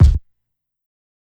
KICK_INJECT.wav